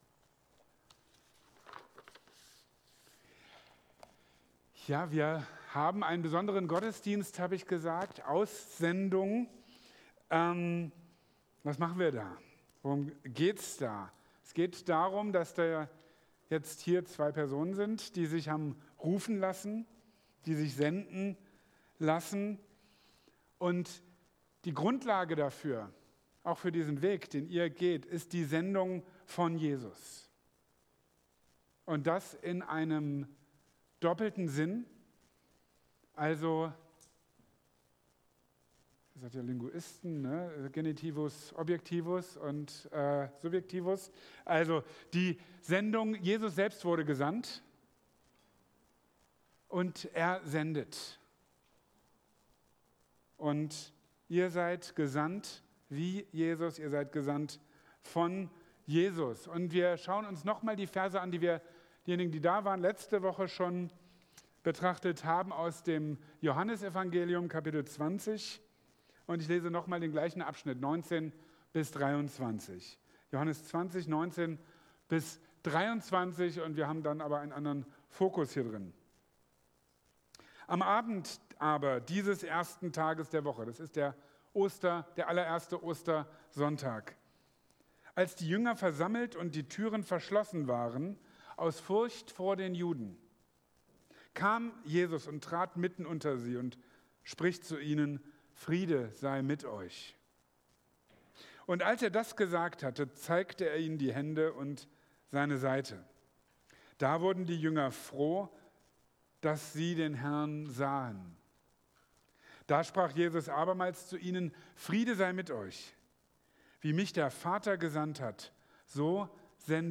In diesem Gottesdienst senden wir zwei Personen aus unserer Gemeinde in einen besonderen Dienst aus. Die Basis für diese Sendung ist die Sendung Jesu - im doppelten Sinn: Sie, und alle Jesusnachfolger, sind gesandt wie Jesus, und gesandt von Jesus.